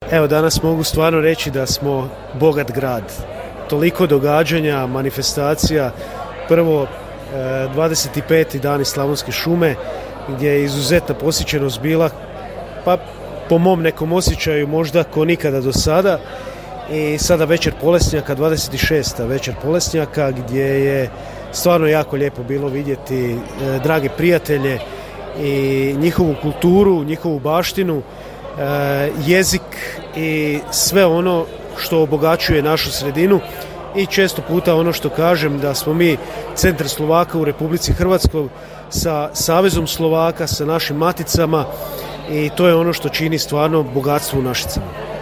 Gradonačelnik Krešimir Kašuba istaknuo je važnost njegovanja ovakvih manifestacija koje obogaćuju naš Grad: